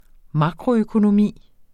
Udtale [ ˈmɑkʁo- ]